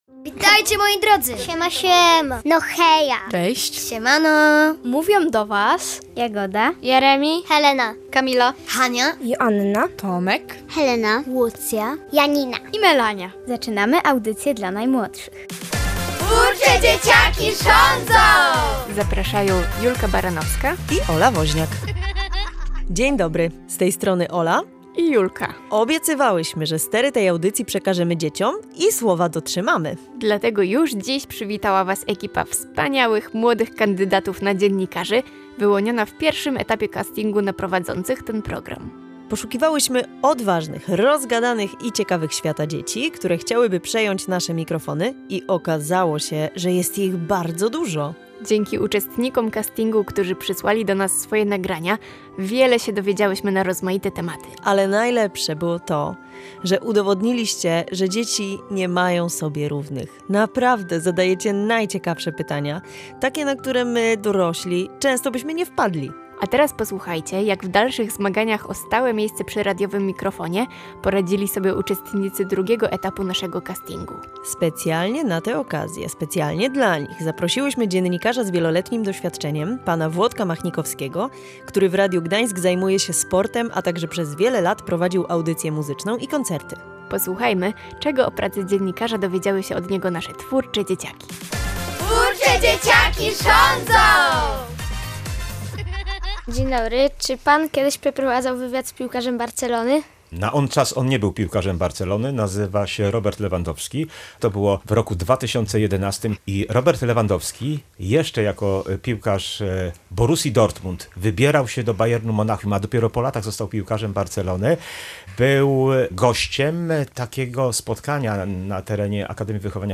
Kandydaci na nowych prowadzących naszą audycję, dzieci w wieku od 9 do 12 lat, przeprowadzają swój pierwszy wywiad!